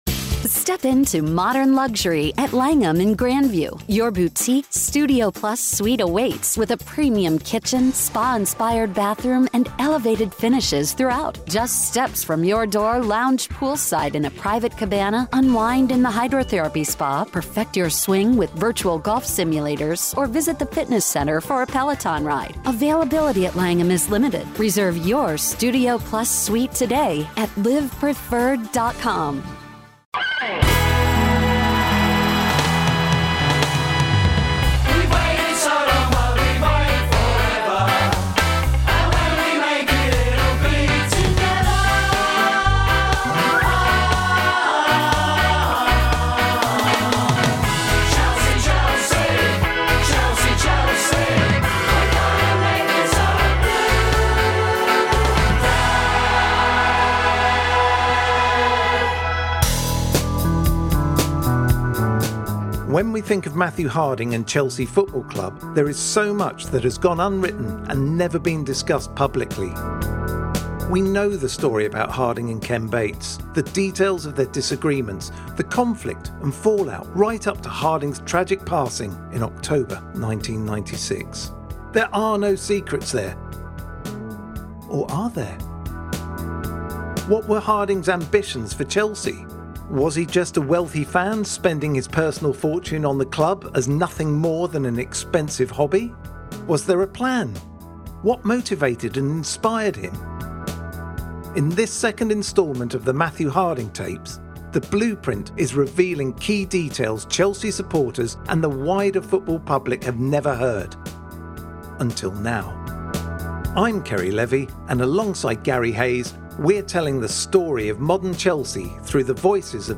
In a varied interview